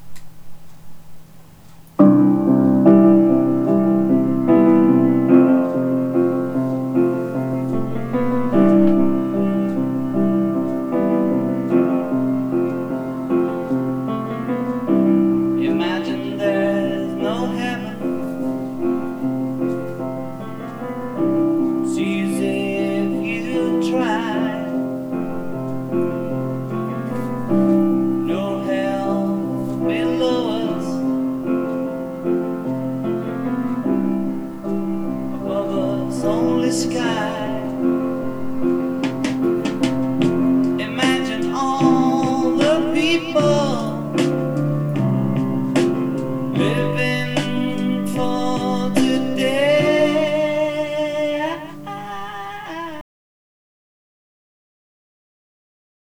同じ音源を２つのスピーカーから出して録音、比較するのです。
TASCAMのレコーダー、DR-07MK2を小さな三脚にセットしました。
目ん玉・キューブの音
出だしのピアノや、後半のドラムスは、明らかに音の膨らみが違います。